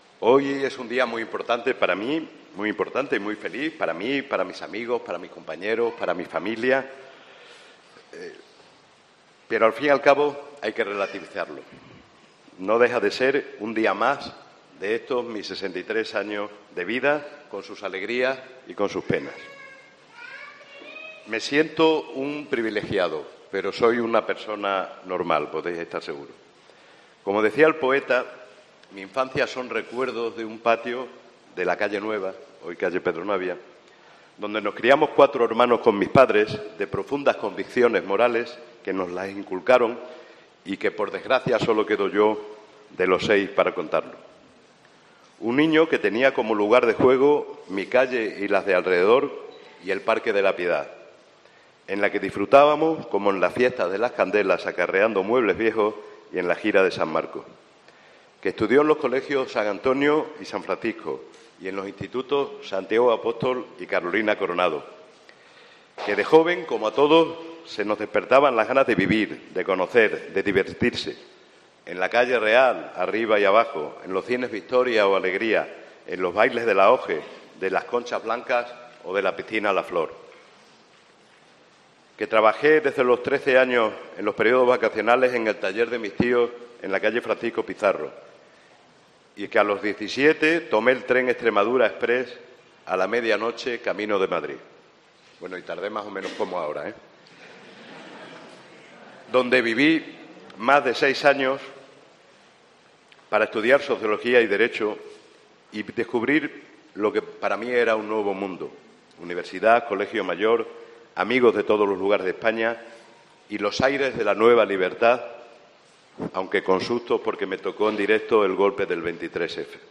Discurso de investidura de José María Ramírez como alcalde de Almendralejo